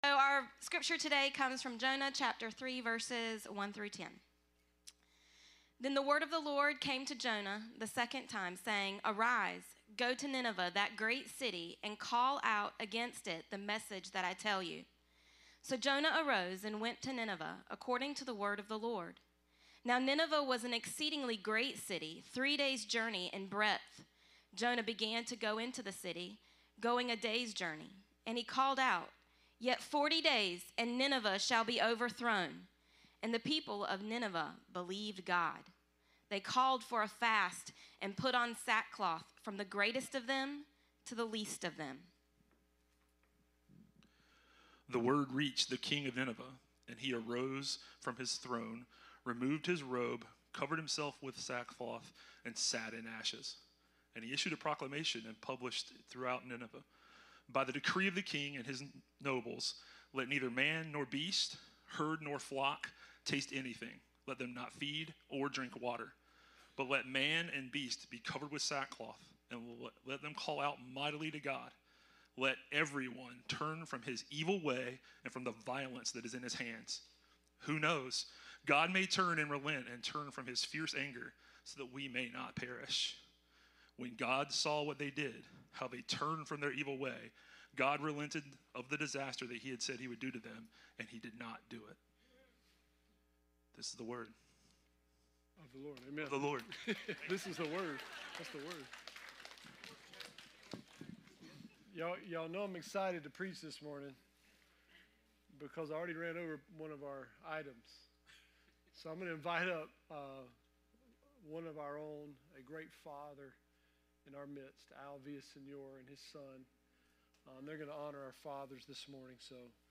Jonah Service Type: Sunday 10am « I Am Jonah Part 5